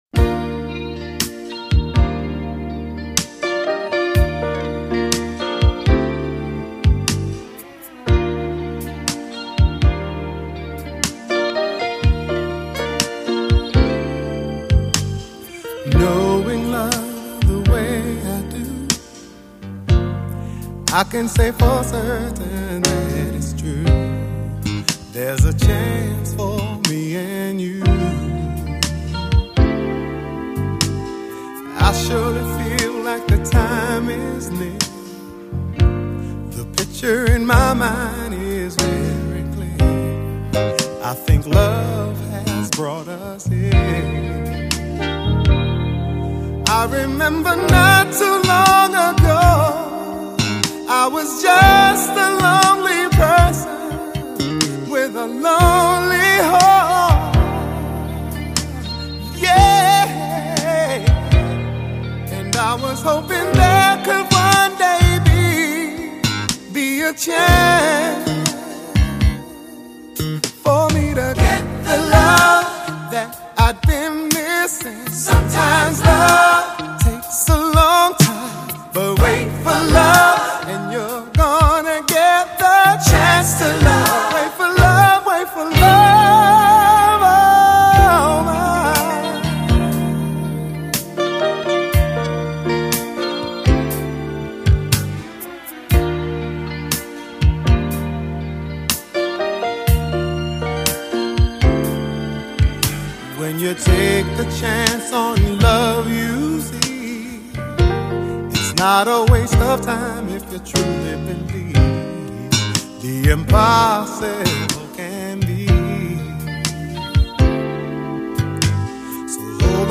他那柔而不亢，油而不腻的高音就会把歌曲中的爱意与情意唱得是十分到位。